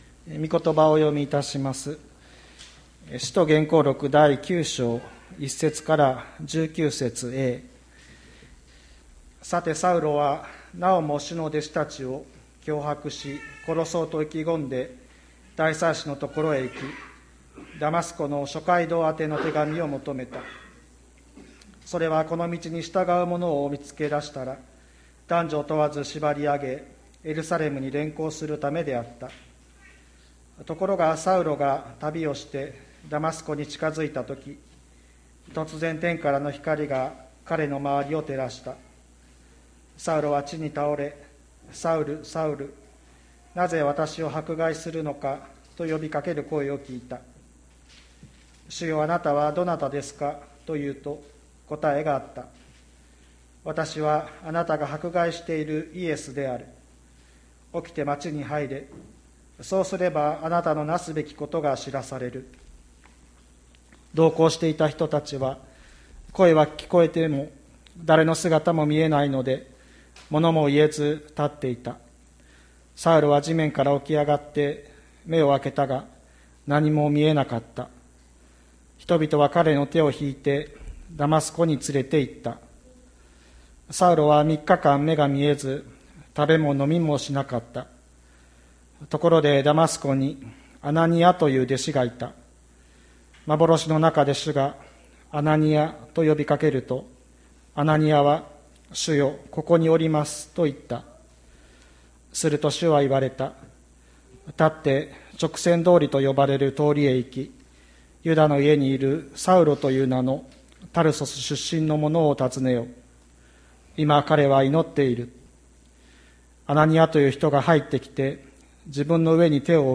2020年08月23日朝の礼拝「あなたを救いたい」吹田市千里山のキリスト教会
千里山教会 2020年08月23日の礼拝メッセージ。